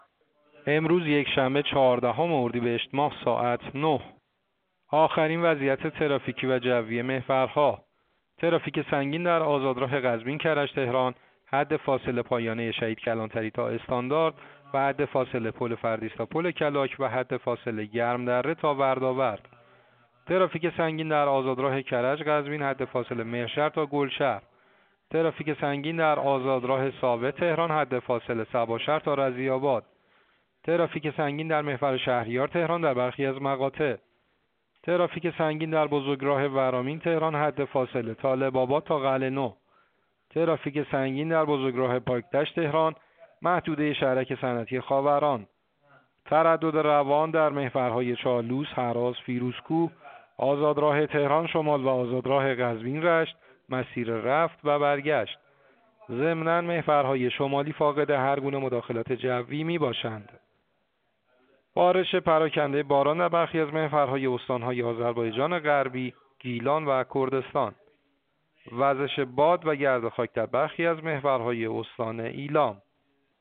گزارش رادیو اینترنتی از آخرین وضعیت ترافیکی جاده‌ها ساعت ۹ چهاردهم اردیبهشت؛